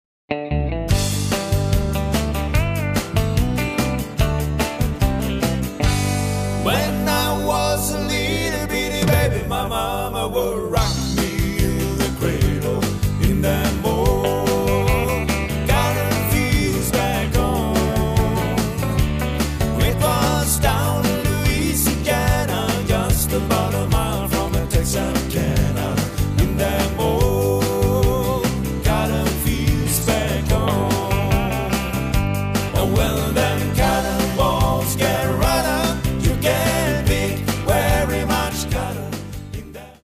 Trubadur/Coverband.